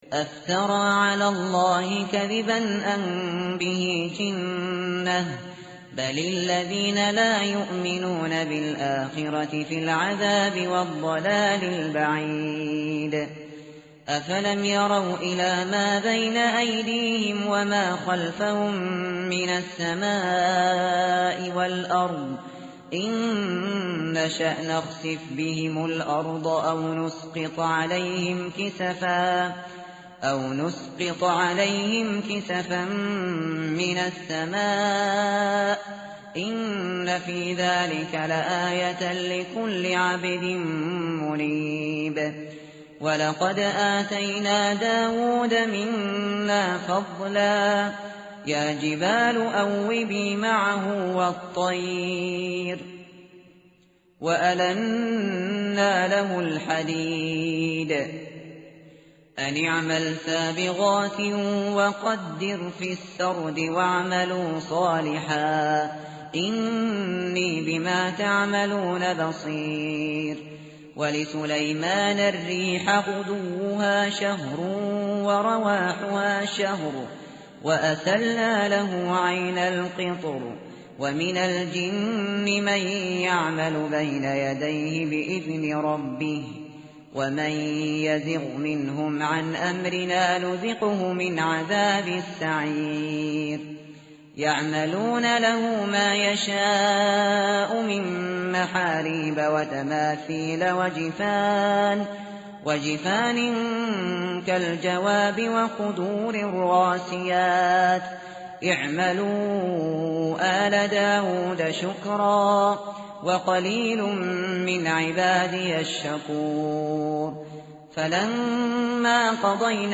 tartil_shateri_page_429.mp3